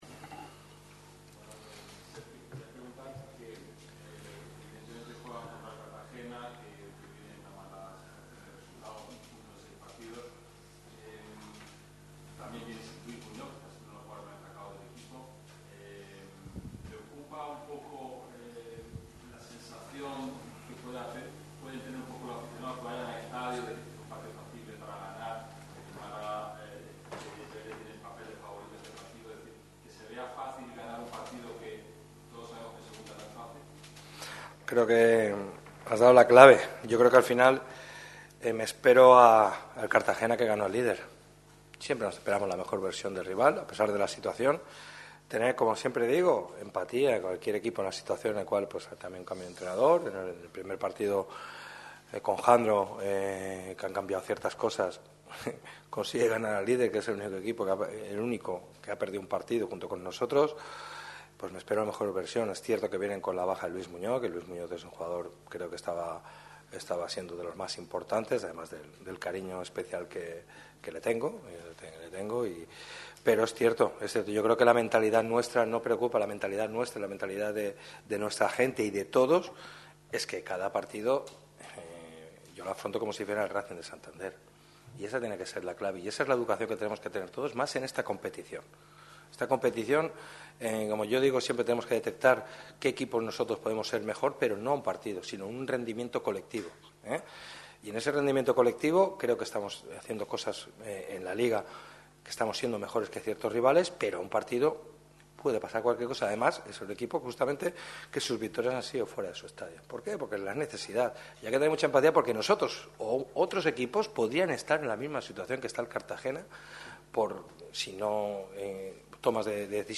El entrenador malaguista ha comparecido ante los medios con motivo de la previa del duelo que enfrentará al Málaga CF contra el colista de LaLiga Hypermotion,